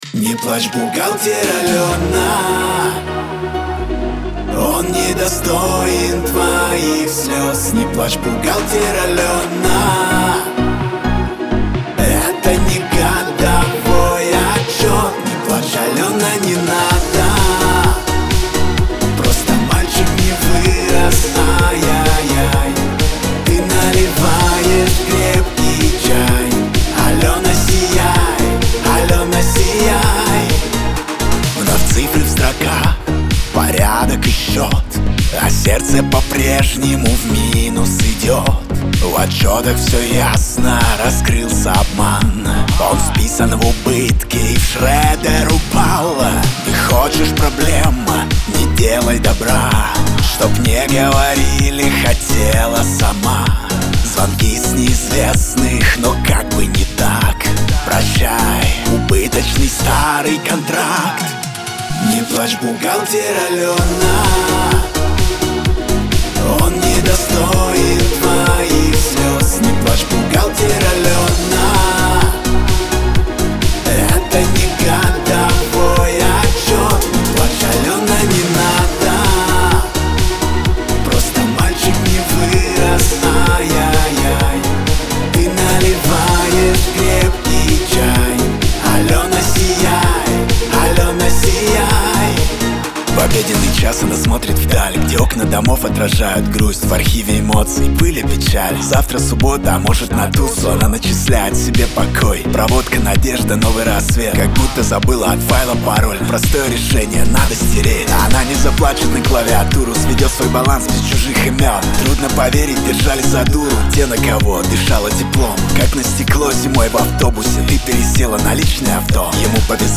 ХАУС-РЭП
эстрада